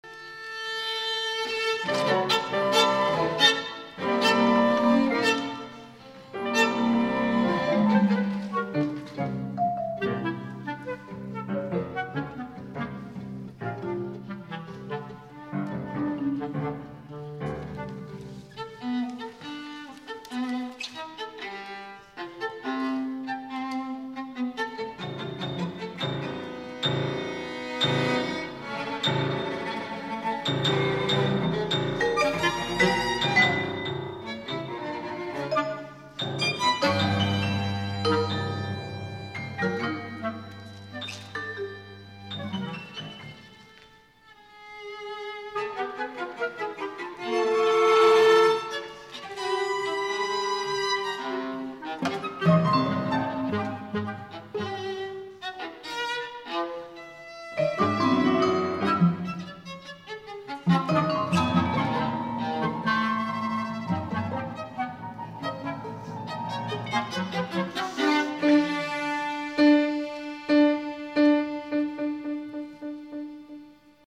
concerti a Perugia
concerto dal vivo intermezzo IV
audio 44kz stereo